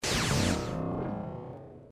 bomb2.wav